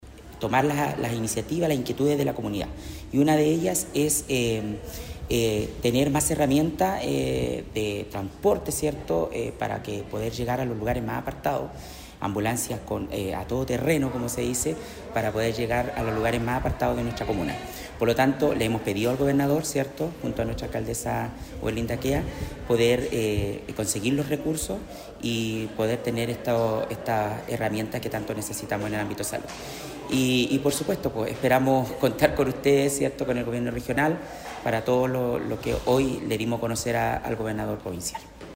En esta misma línea, el concejal Roque González enfatizó la urgencia de contar con herramientas que permitan mejorar el acceso a sectores alejados.
ROQUER-GONZALEZ-CONCEJAL-LA-HIGUERA.mp3